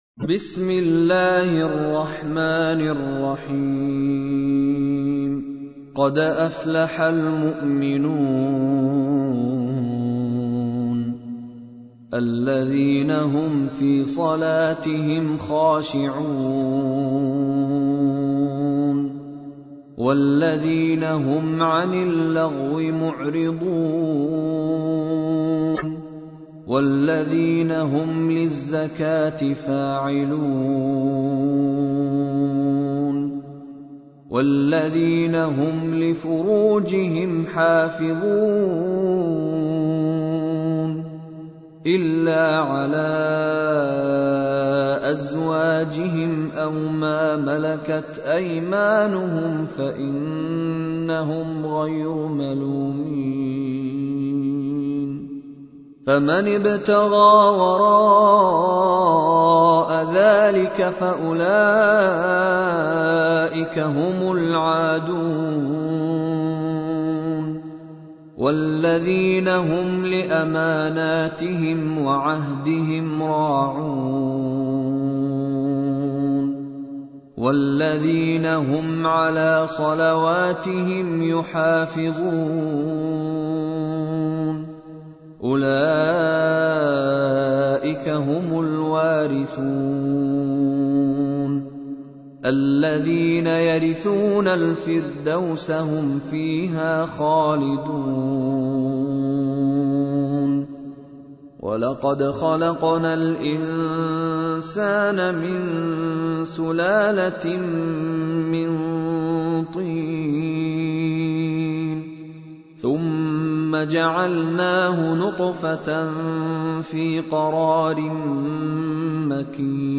تحميل : 23. سورة المؤمنون / القارئ مشاري راشد العفاسي / القرآن الكريم / موقع يا حسين